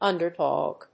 /ˈʌndɝˌdɔg(米国英語), ˈʌndɜ:ˌdɔ:g(英国英語)/
アクセント・音節únder・dòg